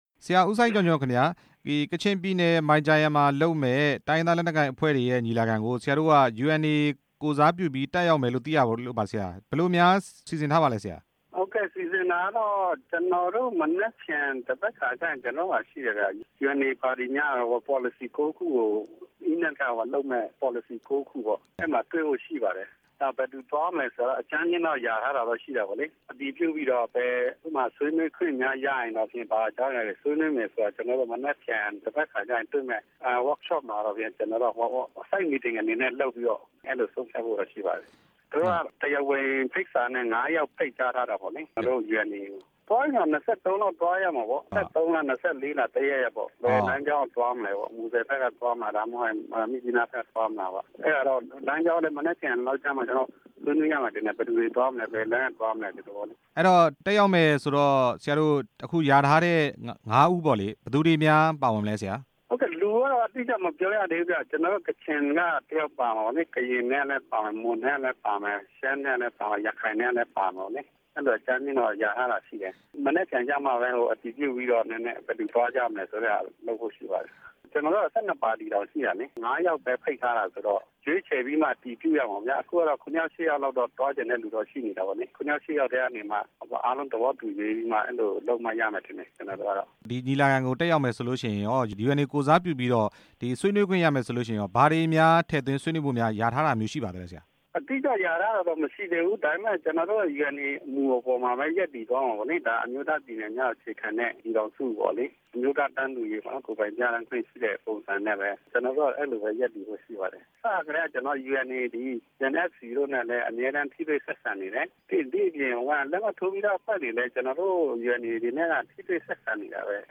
မိုင်ဂျာယန် ညီလာခံ တက်ရောက်မယ့် UNA ကိုယ်စားလှယ်ကို မေးမြန်းချက်